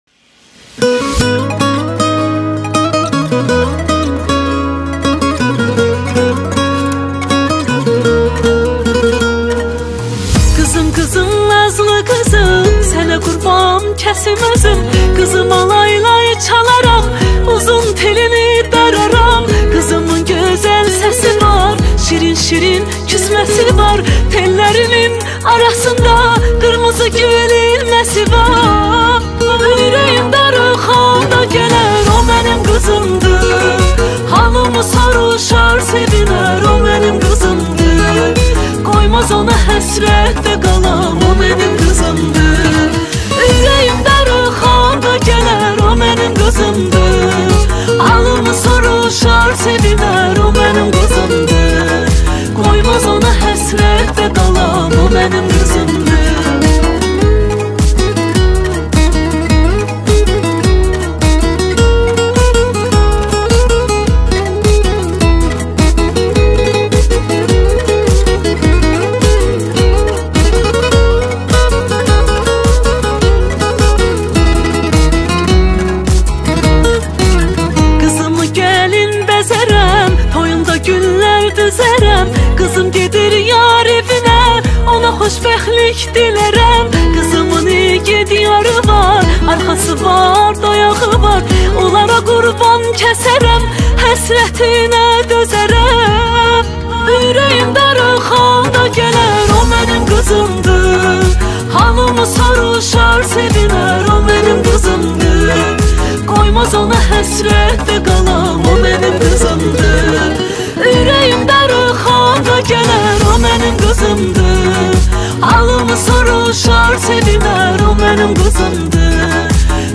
با صدای زن